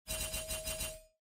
Вы можете слушать онлайн и скачивать различные вопросительные интонации, загадочные мелодии и звуковые эффекты, создающие атмосферу тайны.
Эффект появления вопросов на экране